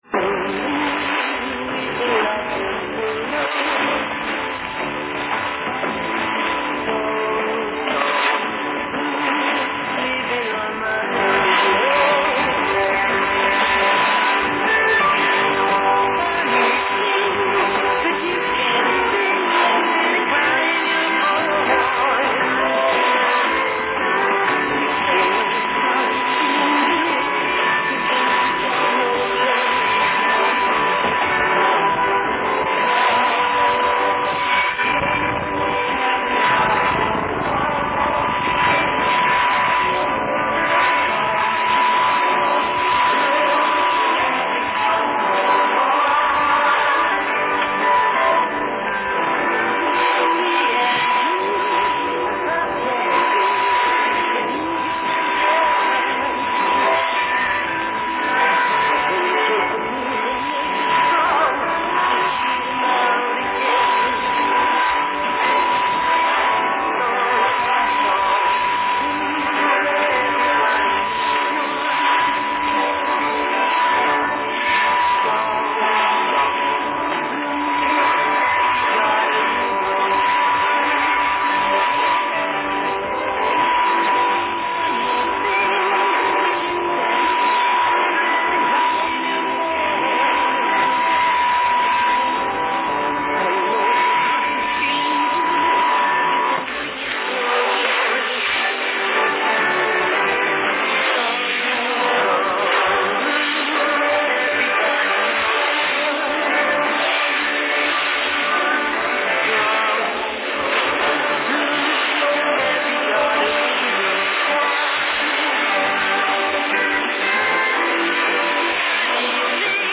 Radio Spaceshuttle Int. konnte ich am 22.10.2010 auf der 6211,0 KHZ um 22.28 UTC mit einem prima Signal loggen – selten genug daß das vorkommt. Auch dieses Tape geriet in Vergessenheit und wurde jetzt bei einer „Cleaning Action“ wieder gefunden. Da das Signal für die sehr späte Zeit extrem gut war, wollte ich euch auch diesen Clip nicht vorenthalten.